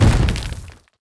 common_fall_3.wav